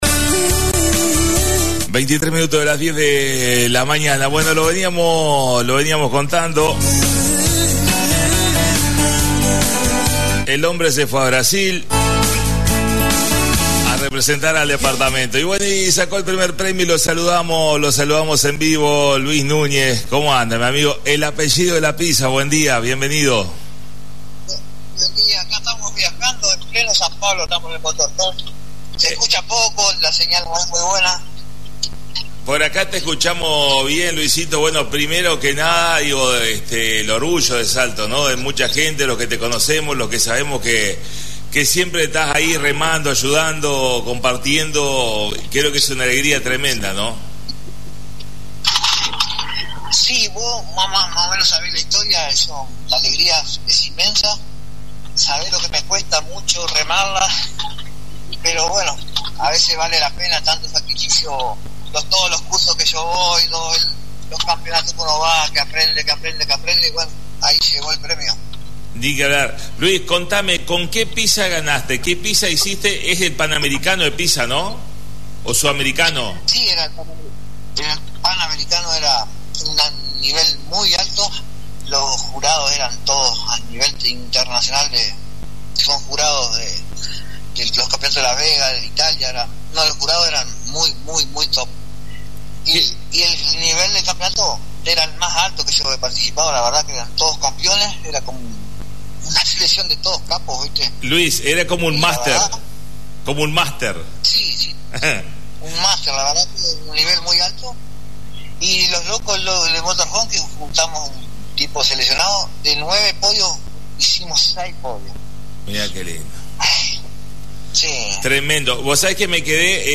Desde San Pablo Brasil Comunicación en vivo